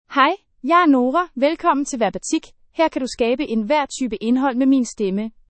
Nora — Female Danish AI voice
Nora is a female AI voice for Danish (Denmark).
Voice sample
Listen to Nora's female Danish voice.
Female
Nora delivers clear pronunciation with authentic Denmark Danish intonation, making your content sound professionally produced.